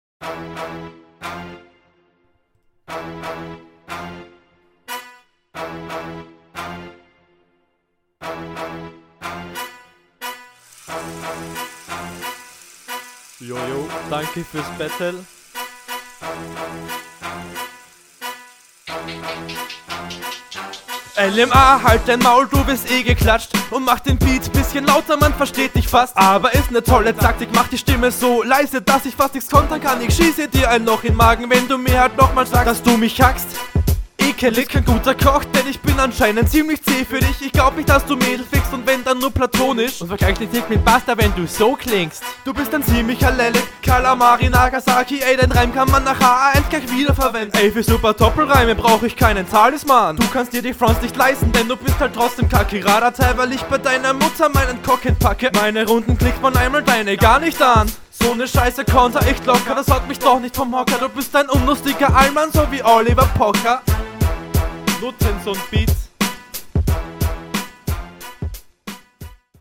Guter Flow. dein Gegner ist da zwar finde ich immernoch besser aber dadurch, dass man …